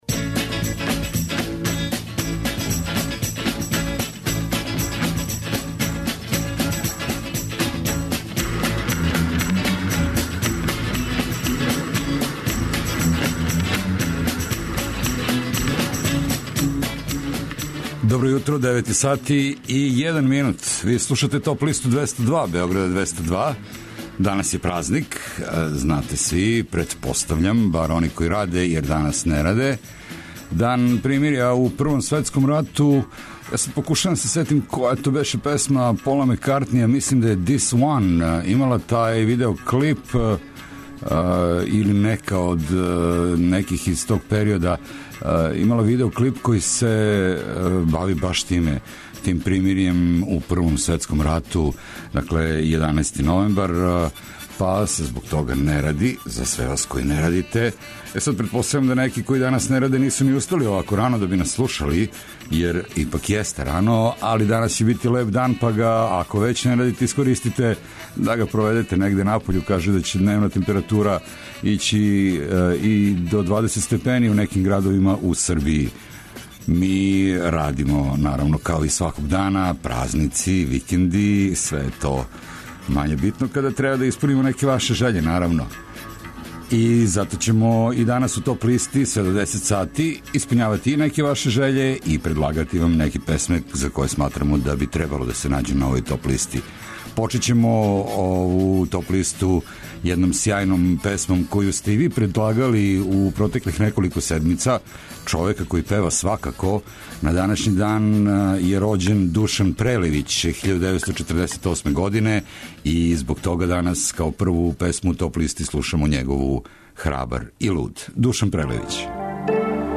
Најавићемо актуелне концерте у овом месецу, подсетићемо се шта се битно десило у историји музике у периоду од 9. до 13. новембра. Ту су и неизбежне подлисте лектире, обрада, домаћег и страног рока, филмске и инструменталне музике, попа, етно музике, блуза и џеза, као и класичне музике.